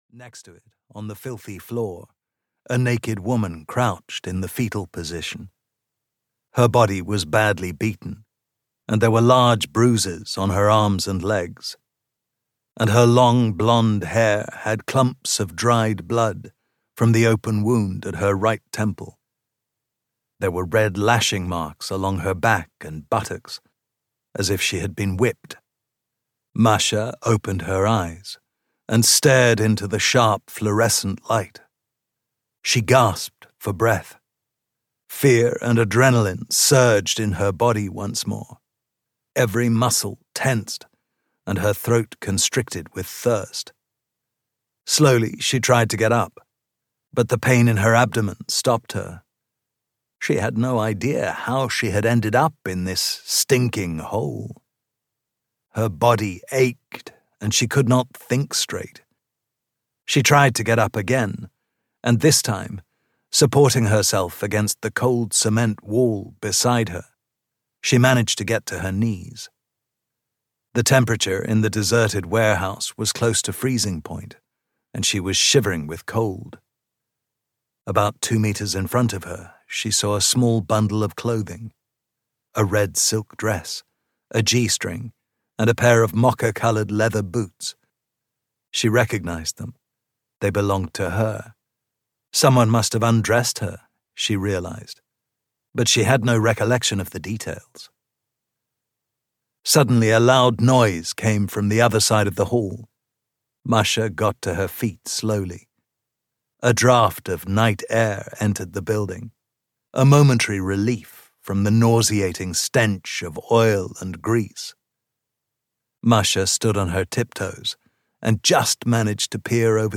Audio knihaThe Reckoning (EN)
Ukázka z knihy